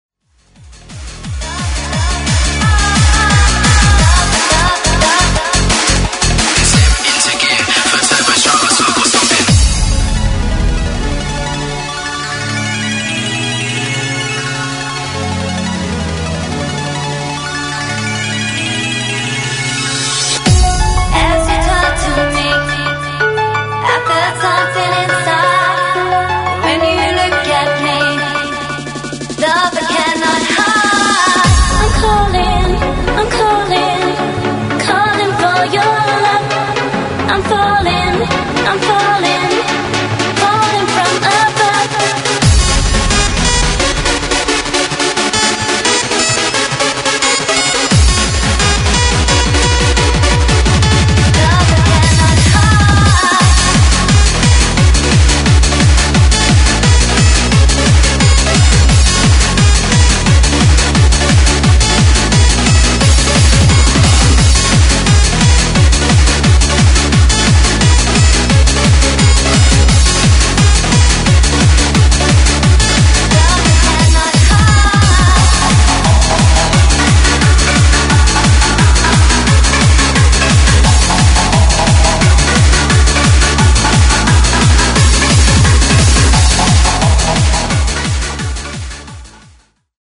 Freeform/Hardcore/Happy Hardcore